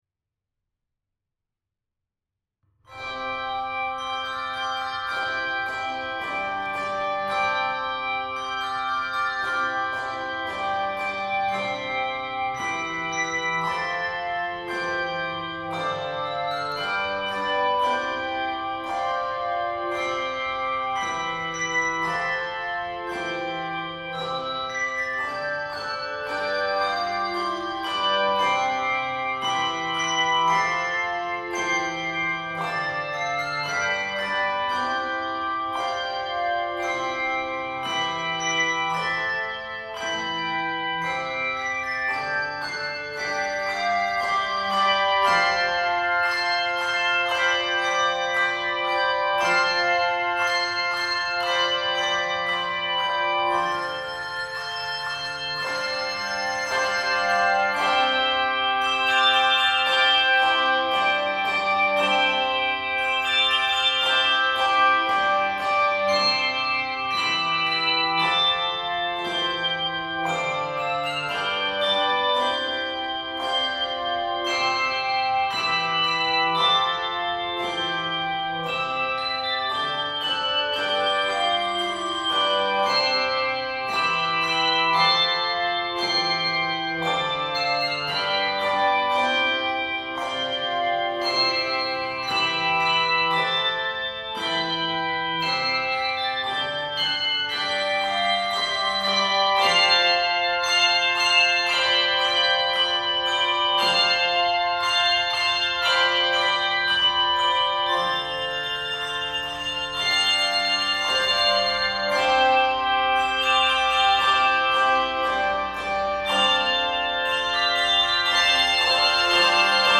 handbells
Key of C Major.